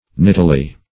nittily - definition of nittily - synonyms, pronunciation, spelling from Free Dictionary Search Result for " nittily" : The Collaborative International Dictionary of English v.0.48: Nittily \Nit"ti*ly\, adv.
nittily.mp3